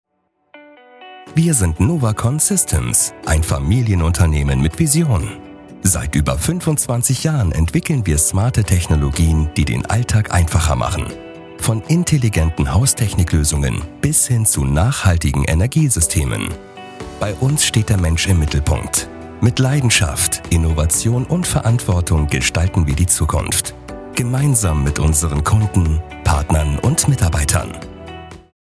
• Eigenes Tonstudio
Imagefilm - nahbar authentisch
Imagefilm-nahbar-authentisch.mp3